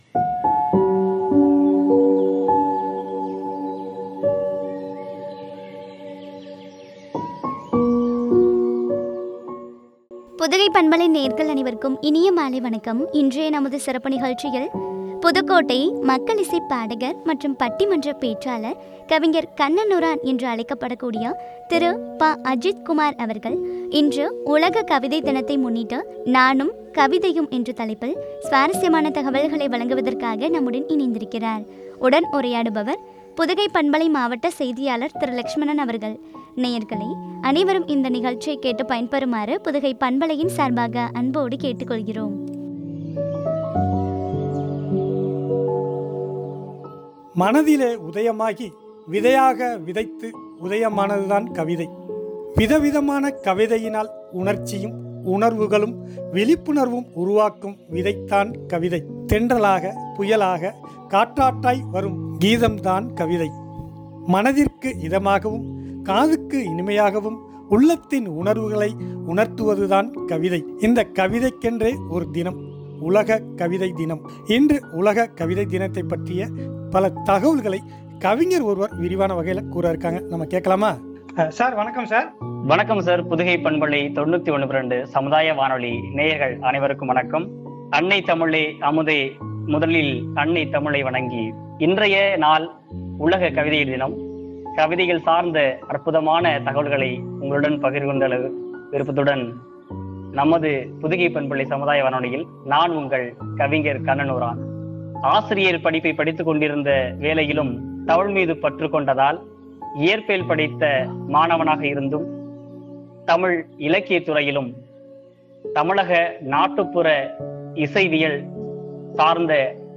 நானும் கவிதையும் என்ற தலைப்பில் வழங்கிய உரையாடல்.